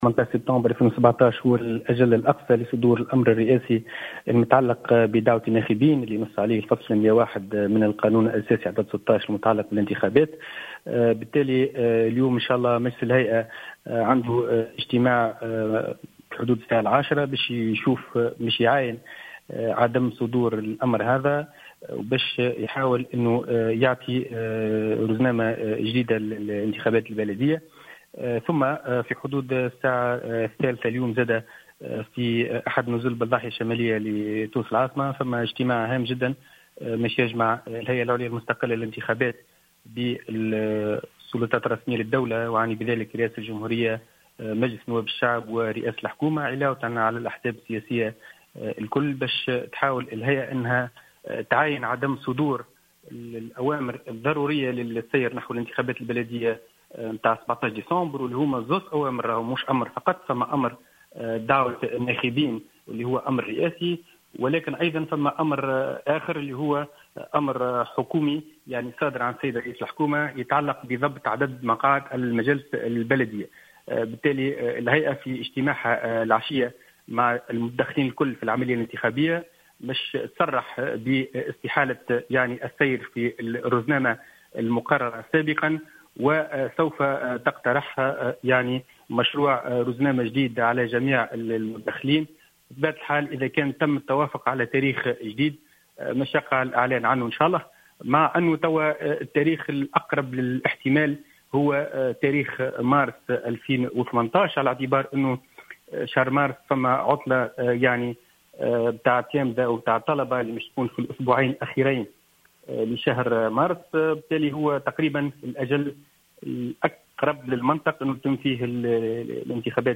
قال العضو بالهيئة العليا المستقلة للانتخابات، فاروق بوعسكر اليوم الاثنين في تصريح لـ "الجوهرة اف أم" إن اجتماعا هاما سينعقد في وقت لاحق من اليوم حول تغيير موعد الانتخابات البلدية والتوافق بشأن موعد جديد.